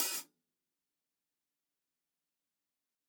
TUNA_HH_3.wav